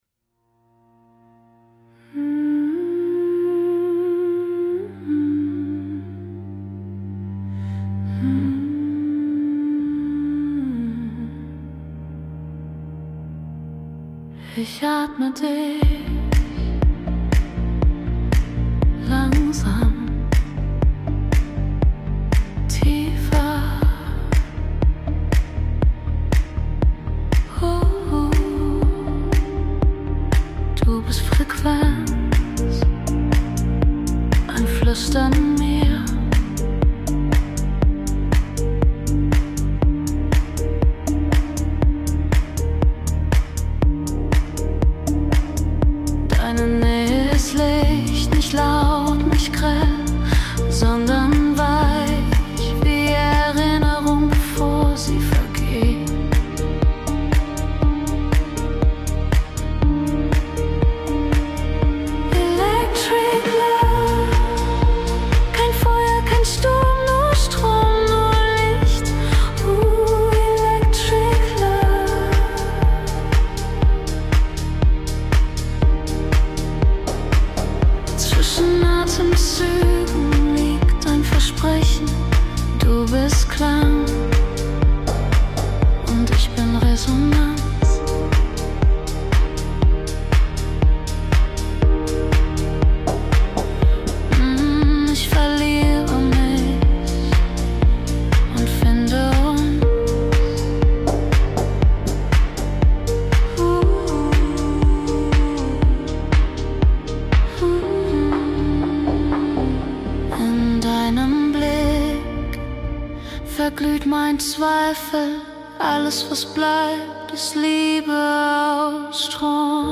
Electric Love“ … weich, warm und voller Liebe